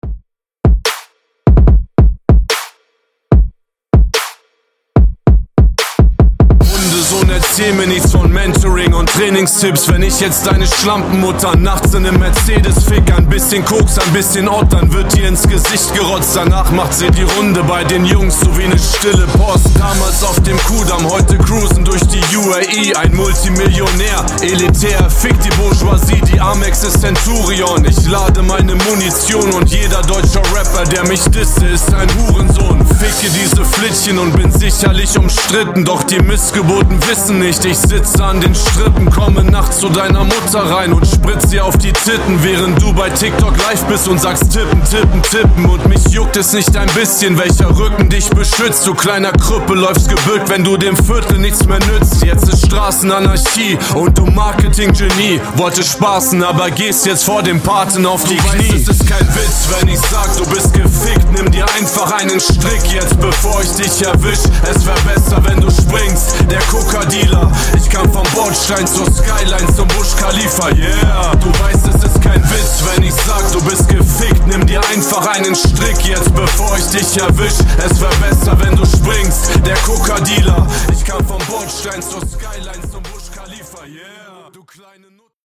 Genres: GERMAN MUSIC , RE-DRUM , TOP40
Clean BPM: 112 Time